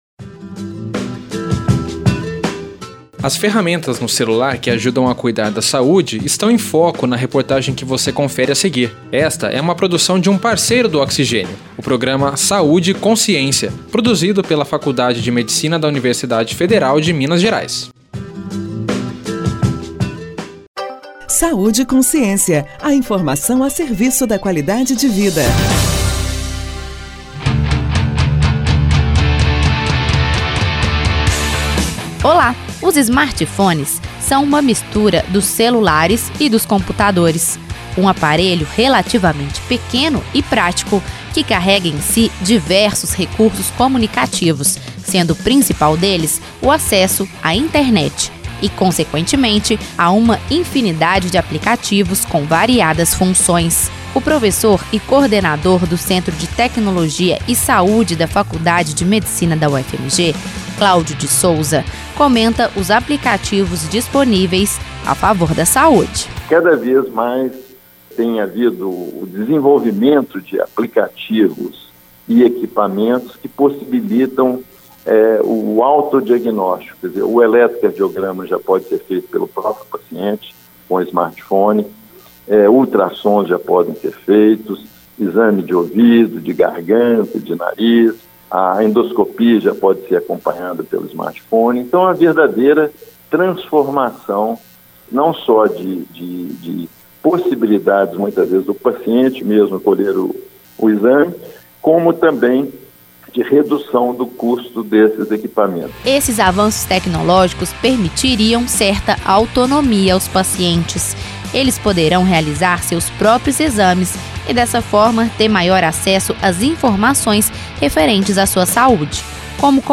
Reportagem
27-05-Reportagem-Saude-Aplicativos.mp3